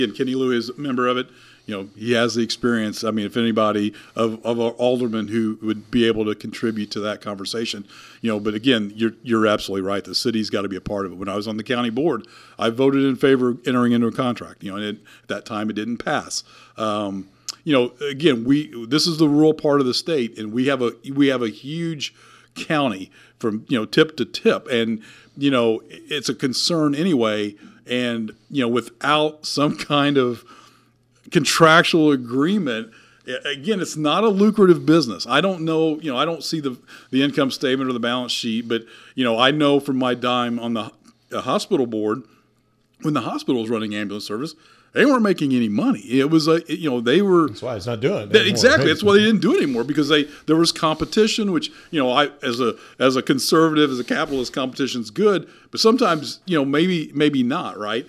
Speaking on our podcast “Talking about Vandalia,” Mayor Doug Knebel says he knows first hand about past efforts by the Fayette County Board to come up with a permanent solution and vows to have the City of Vandalia be part of any solution to this problem.